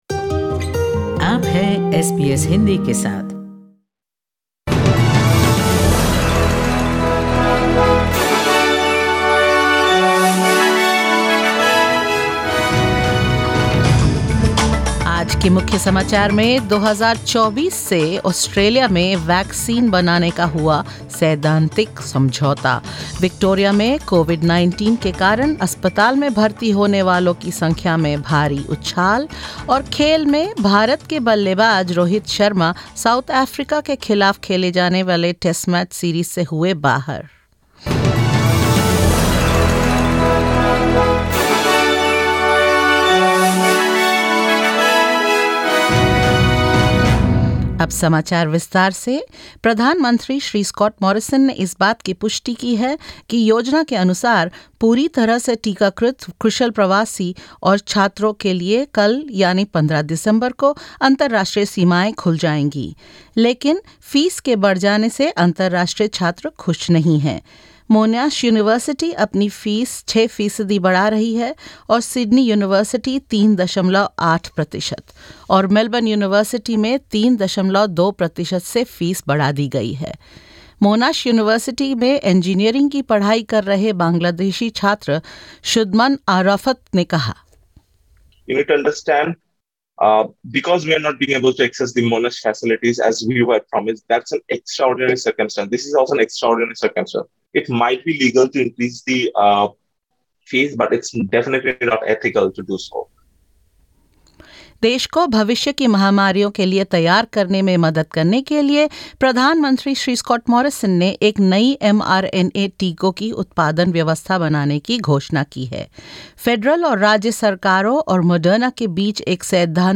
In this latest SBS Hindi news bulletin of Australia and India: Moderna signs an in-principle agreement for Victoria to produce mRNA vaccines domestically; COVID-related hospitalisations in Victoria reach the highest level in almost a month; New South Wales to ease their COVID-19 restrictions despite rising case numbers and more.